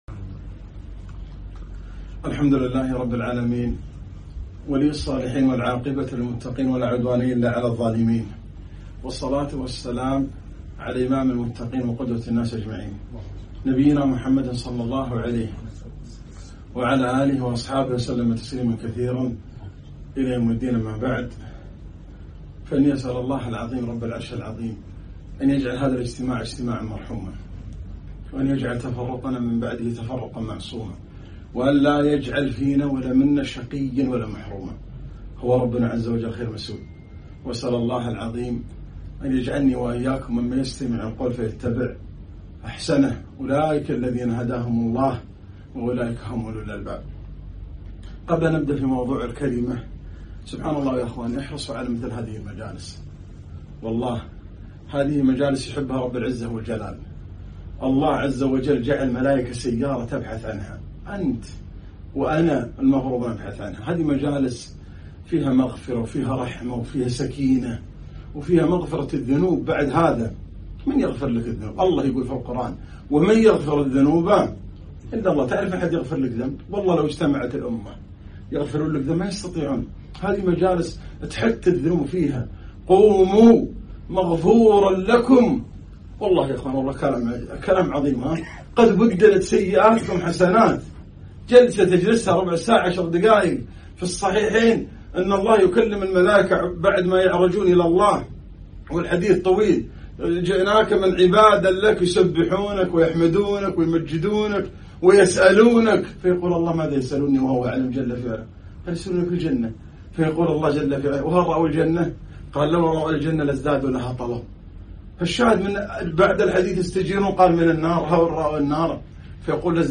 كلمة - سرية الرجيع